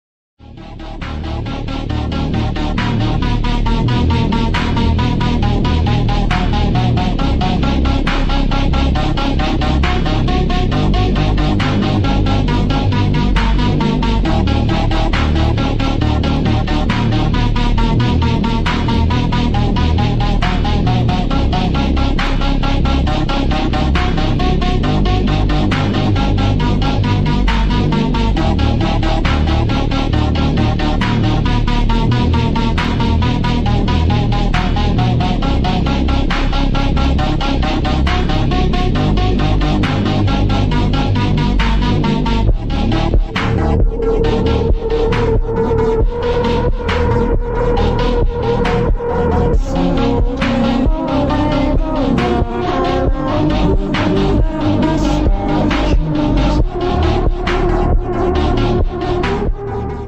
⚠ Warning ⚠ Extreme bassbossted